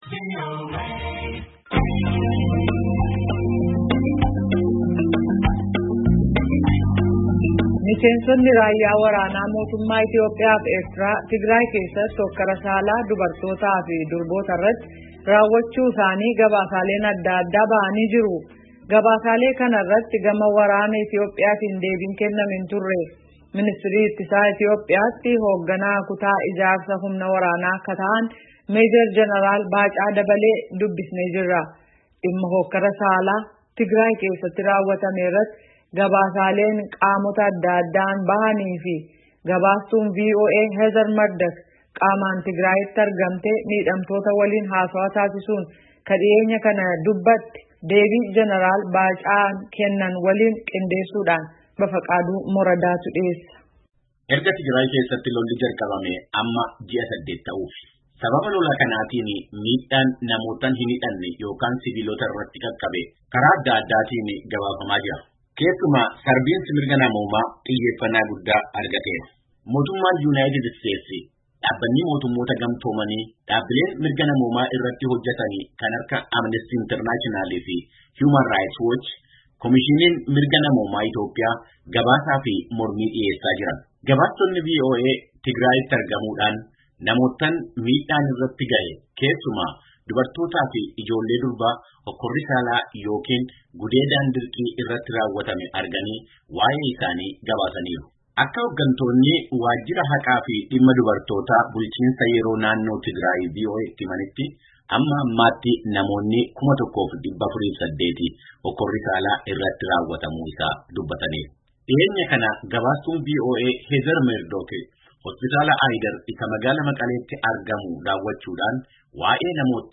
Gaafii fi Deebii, Meejer Jeneraal Baacaa Dabalee Wajjin